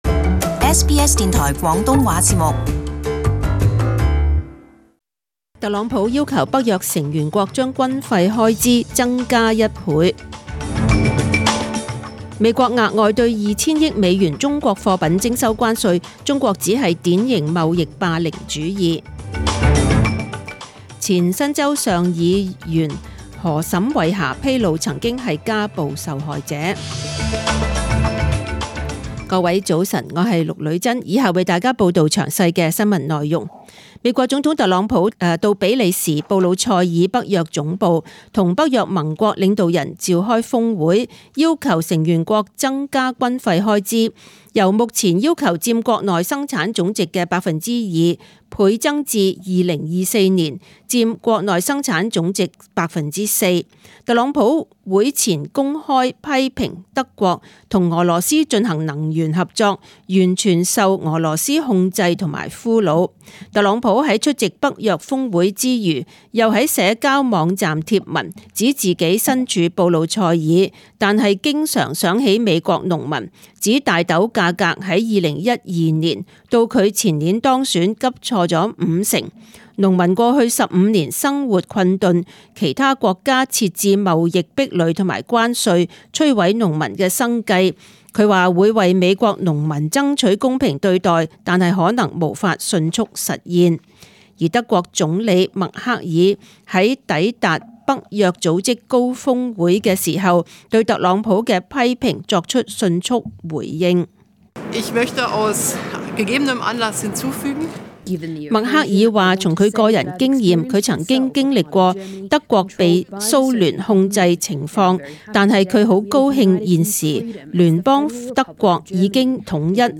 10 am News Bulletin